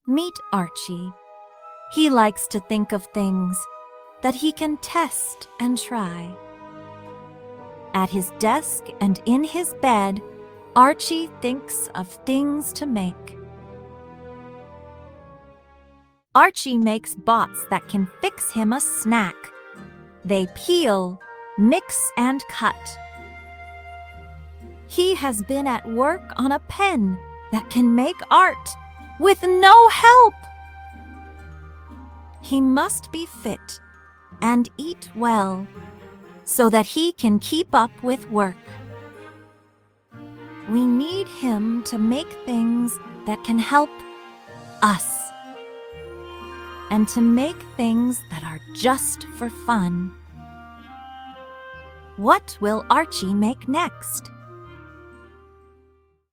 Words containing the 'ee' vowel team, consistently producing the long /iː/ sound.